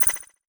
Message Bulletin Echo 6.wav